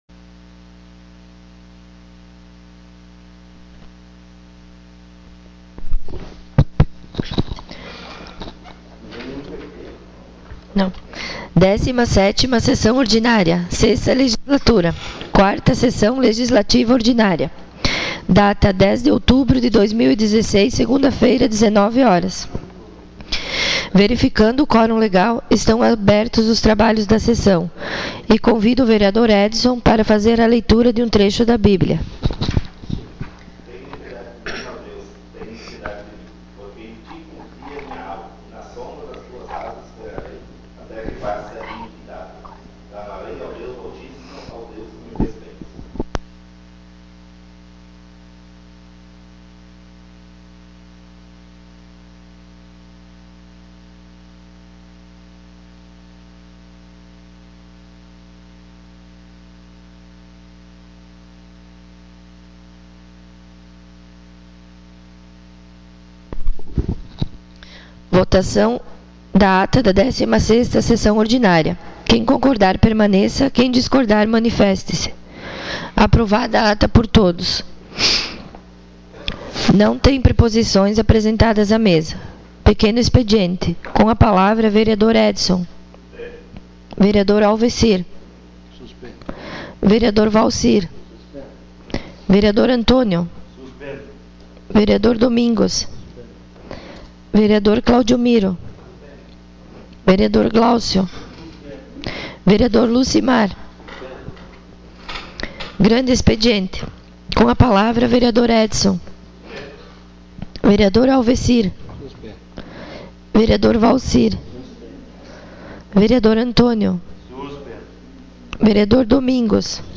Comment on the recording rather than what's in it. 17ª SESSÃO ORDINÁRIA 10.10.16